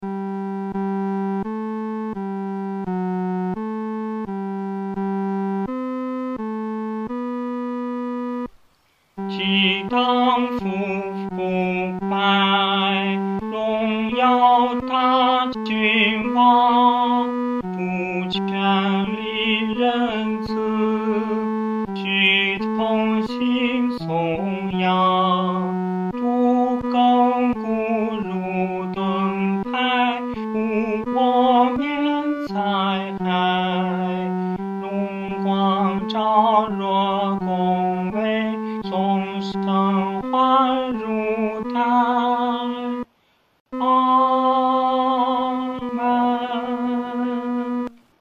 合唱
男高
本首圣诗由石家庄圣诗班（二组）录制